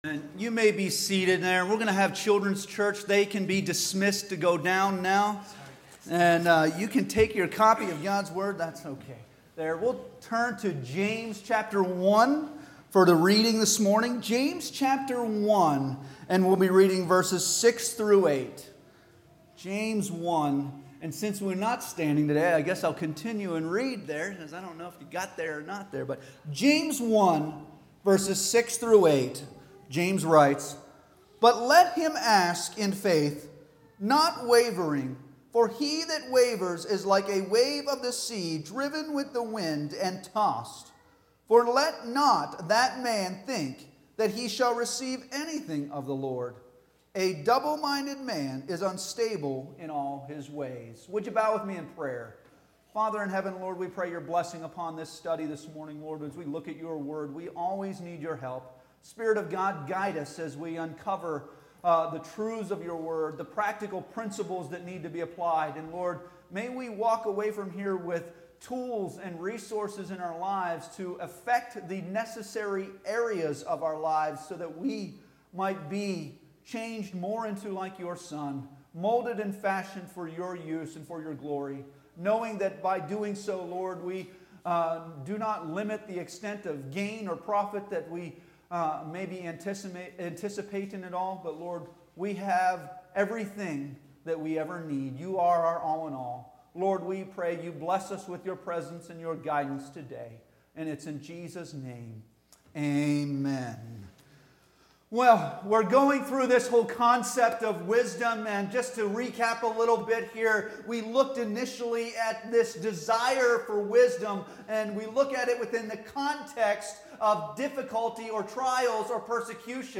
James 1:6-8 Service Type: Sunday Morning Worship Bible Text